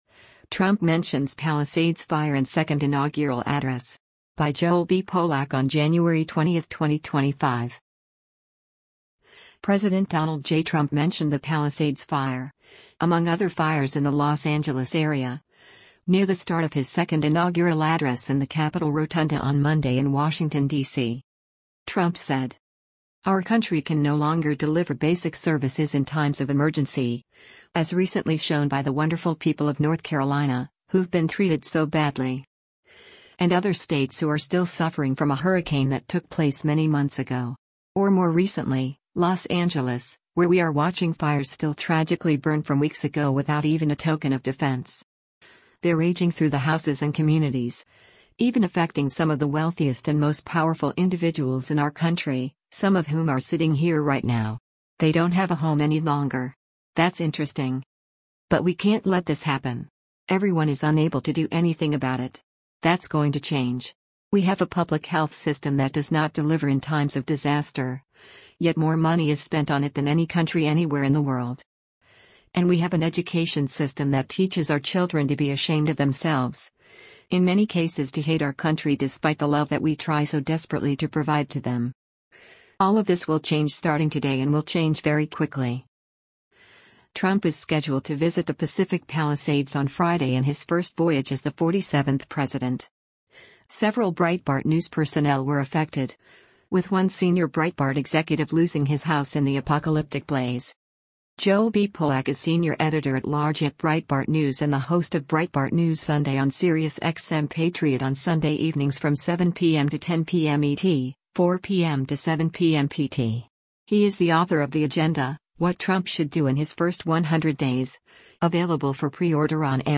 President Donald J. Trump mentioned the Palisades Fire, among other fires in the Los Angeles area, near the start of his Second Inaugural address in the Capitol Rotunda on Monday in Washington, D.C.